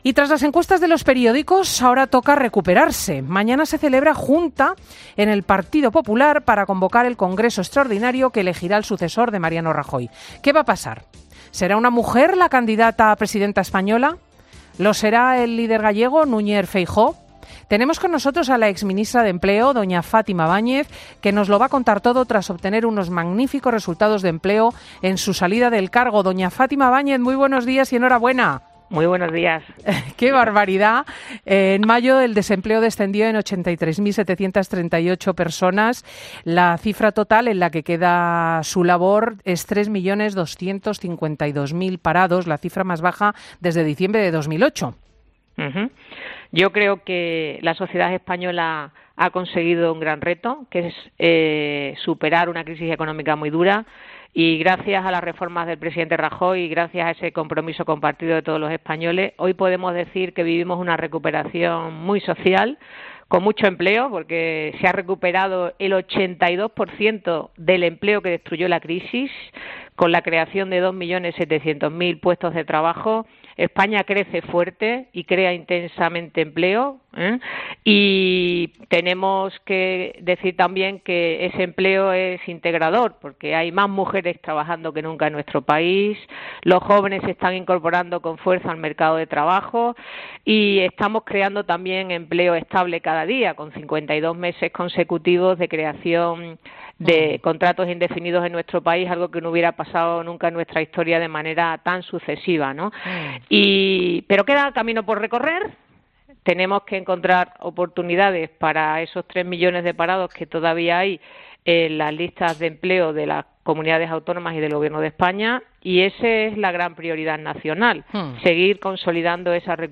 La exministra de Empleo valora las primeras decisiones del nuevo Gobierno